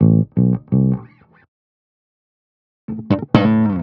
02 Bass Loop A.wav